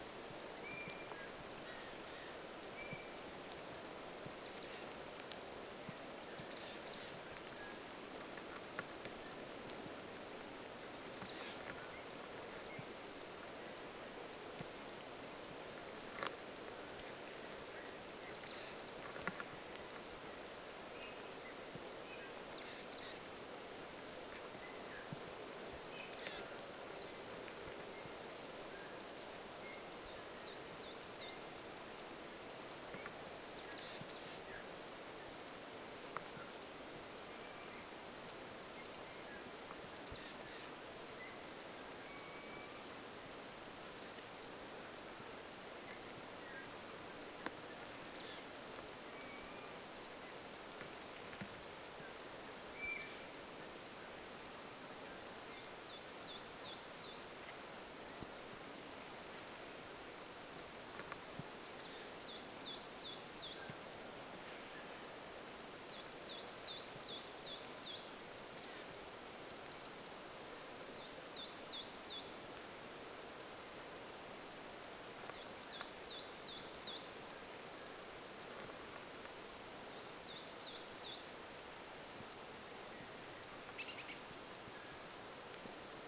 linturuokinnan ääniä
linturuokinnan_aania_1.mp3